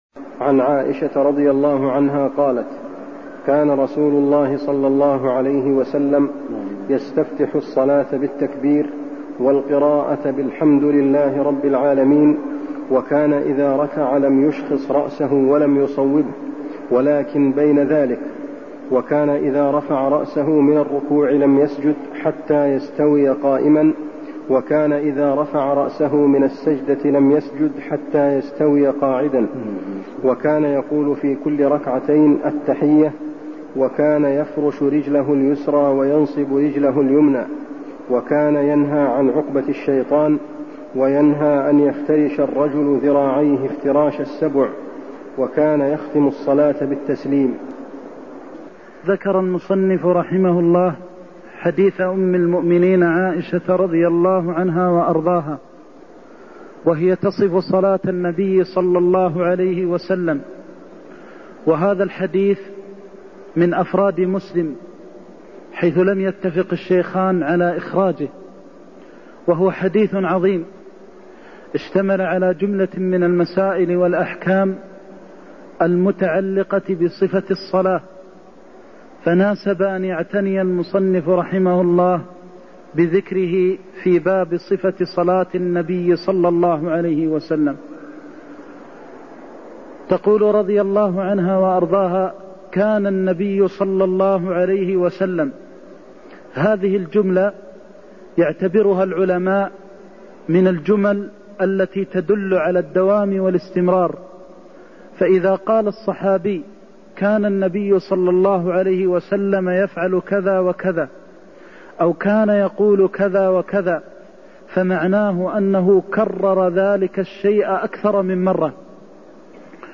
المكان: المسجد النبوي الشيخ: فضيلة الشيخ د. محمد بن محمد المختار فضيلة الشيخ د. محمد بن محمد المختار كان يستفتح الصلاة بالتكبير والقراءة بالحمد لله رب العا (79)